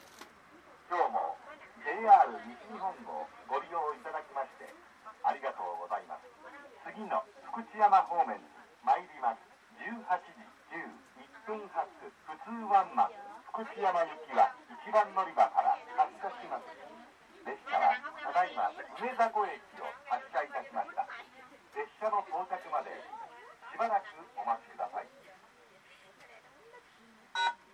この駅では接近放送・予告放送が導入されています。
予告放送普通ワンマン　福知山行き予告放送です。